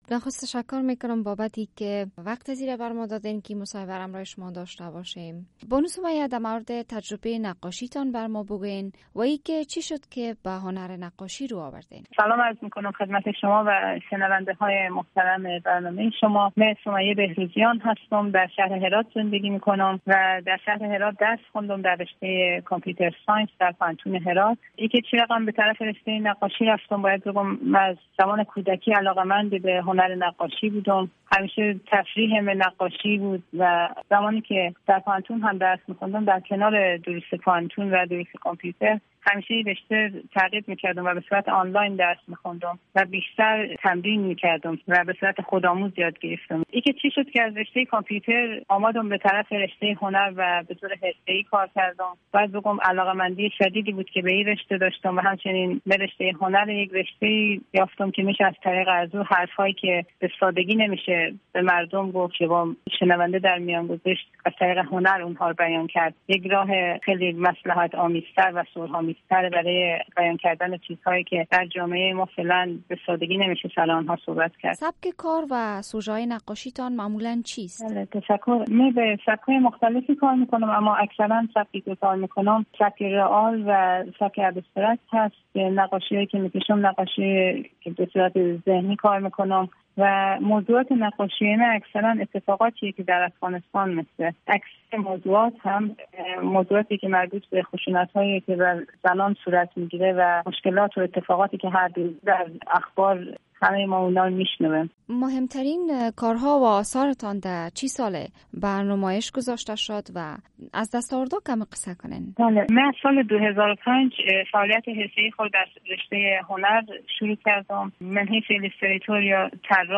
شرح کامل مصاحبه